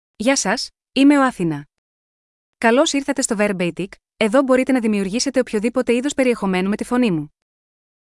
AthinaFemale Greek AI voice
Athina is a female AI voice for Greek (Greece).
Voice sample
Listen to Athina's female Greek voice.
Athina delivers clear pronunciation with authentic Greece Greek intonation, making your content sound professionally produced.